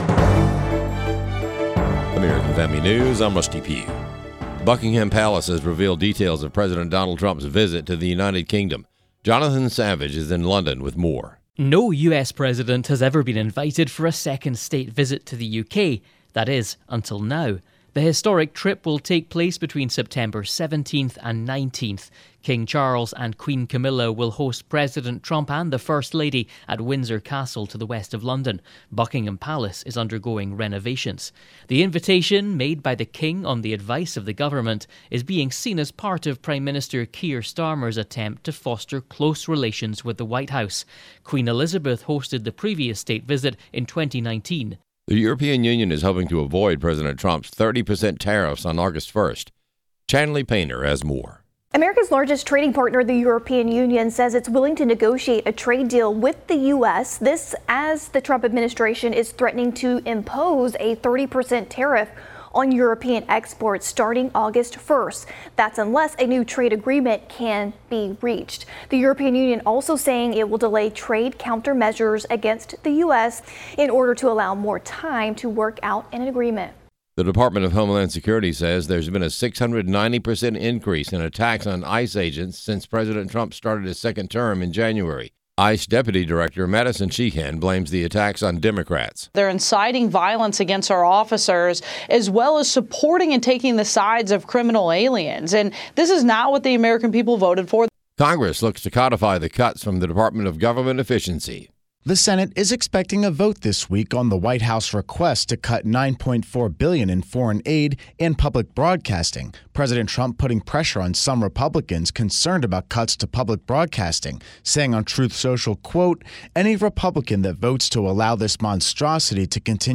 AI hosts